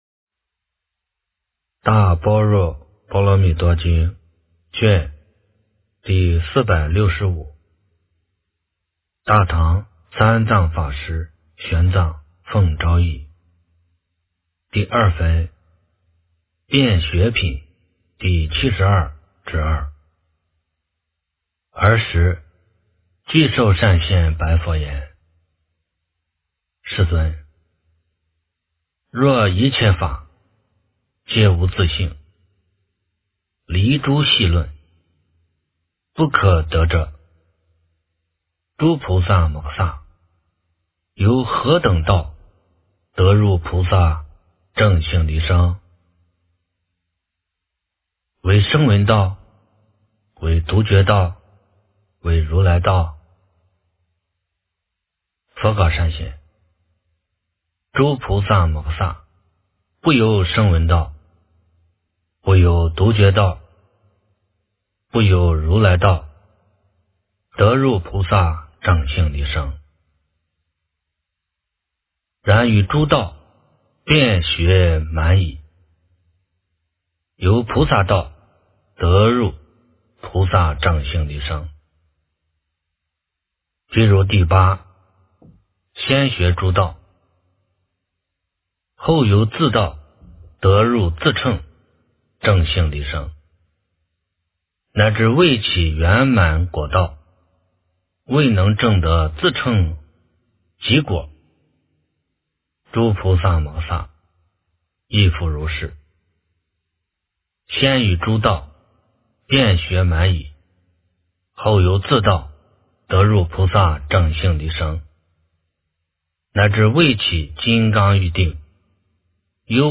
大般若波罗蜜多经第465卷 - 诵经 - 云佛论坛